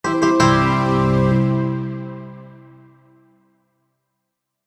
Victory SoundFX2.wav